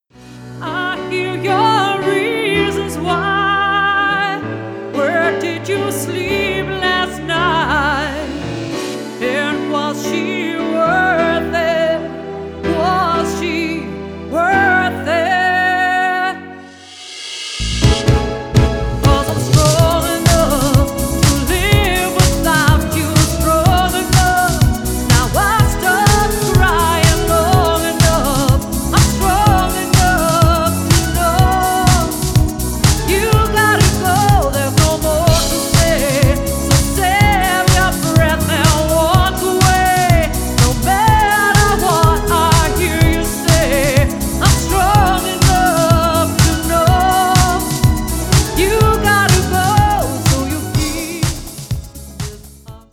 a highly experienced and professional two-piece band